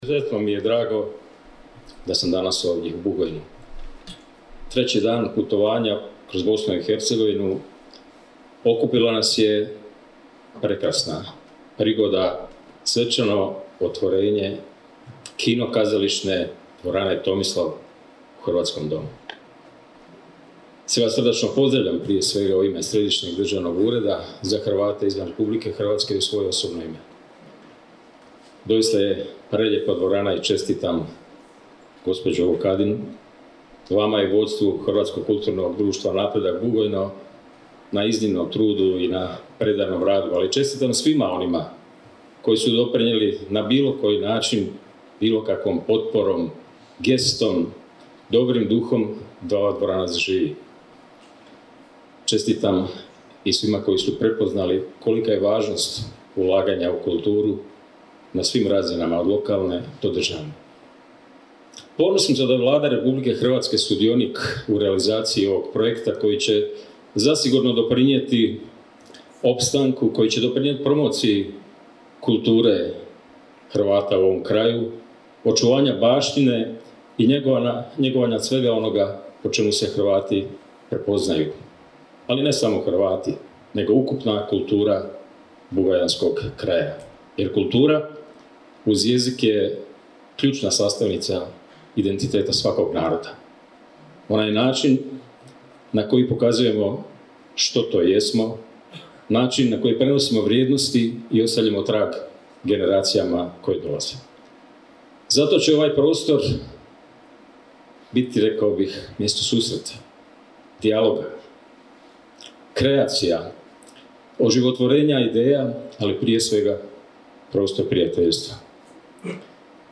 Državni tajnik Središnjeg državnog ureda Vlade Republike Hrvatske za Hrvate izvan Hrvatske Zvonko Milas je uputio pozdrave u ime Vlade Republike Hrvatske, najvećeg pokrovitelja obnove i svečanosti otvaranja.
Zvonko-Milas-Središnji-ured-za-Hrvate-izvan-HR.mp3